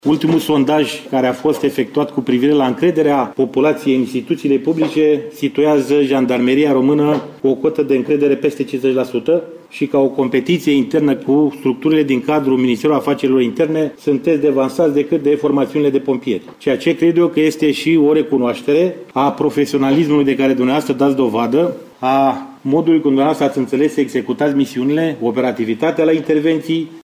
Prefectul Nicolae Ciobanu a vorbit în faţa asistenţei numeroase despre rolul important pe care îl joacă jandarmeria pentru creşterea gradului de siguranţă al cetăţeanului, dar şi pentru siguranţa principalelor instituţii ale statului:
Ciobanu-felicitari.mp3